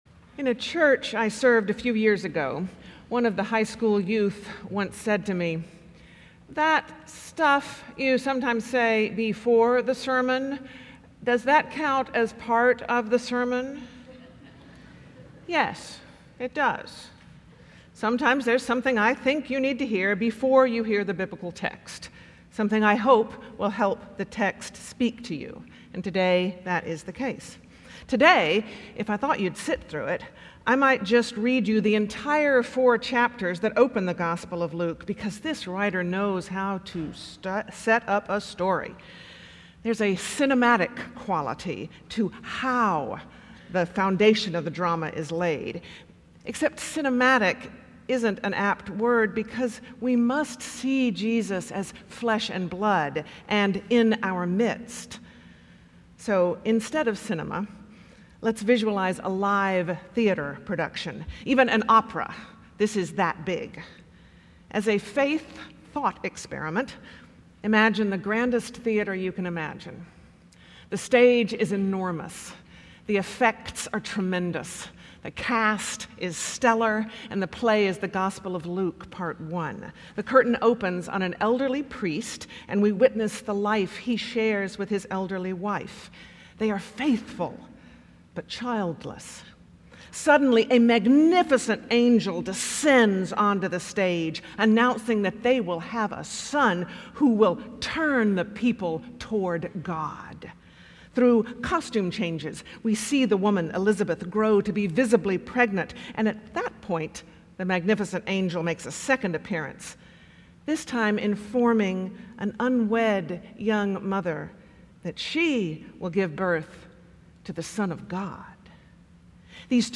Morningside Presbyterian Church - Atlanta, GA: Sermons: TEMPTATION BATS FIRST
Morningside Presbyterian Church - Atlanta, GA